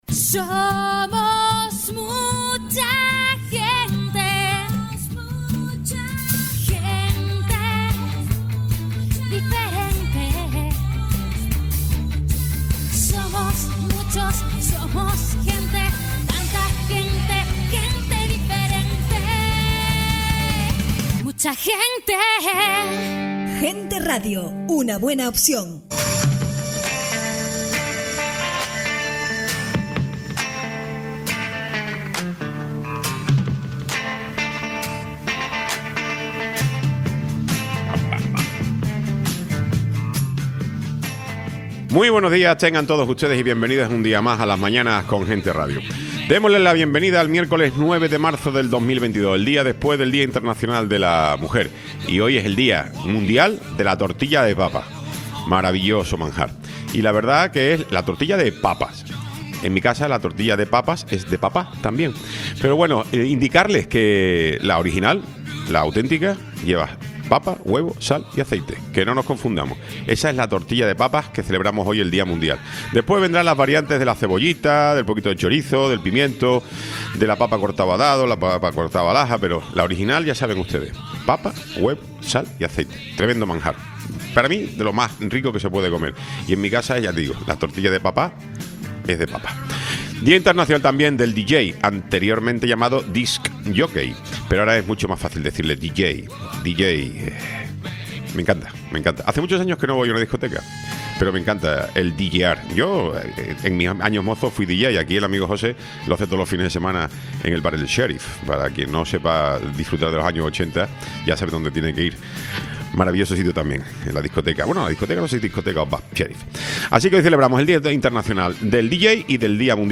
Tiempo de entrevista